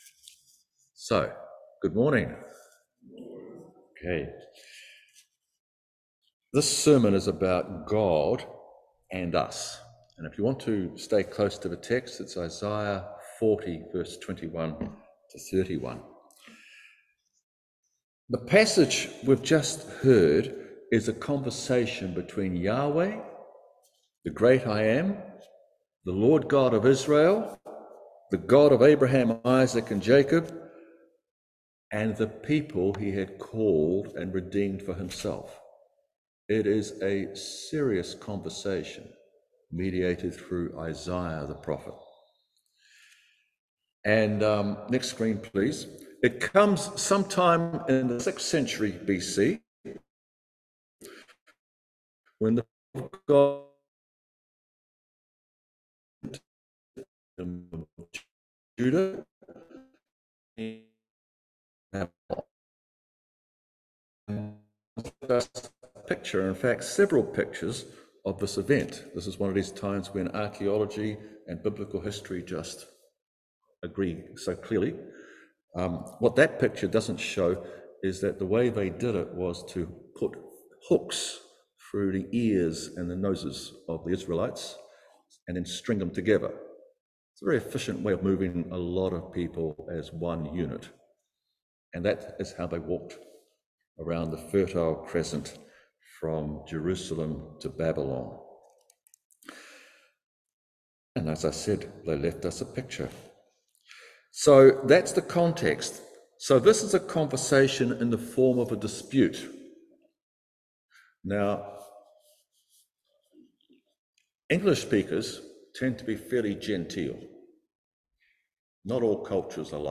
Isaiah 40:21-31 Service Type: Holy Communion Knowing God as creator of the cosmos and His greatness.